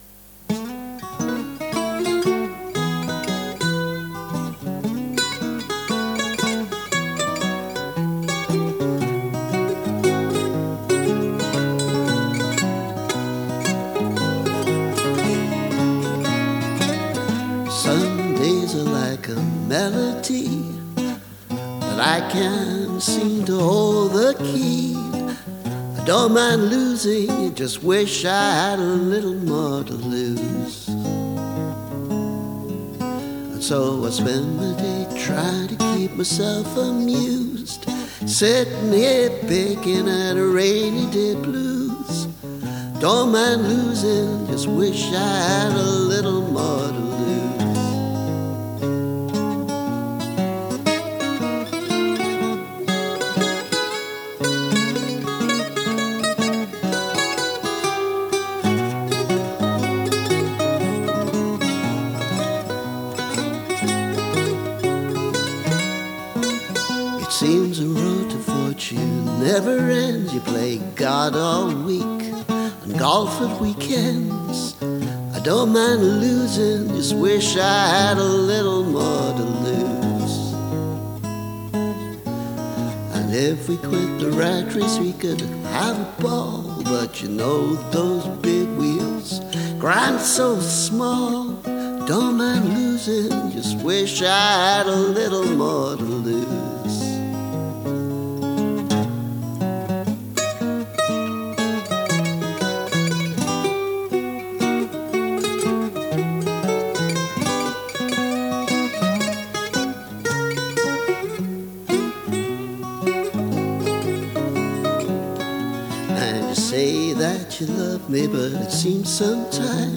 This is the same version with some overdubbed bouzouki: an instrument I’d only recently added to my arsenal, so not very well executed, but I think it might go quite well with a bit of work.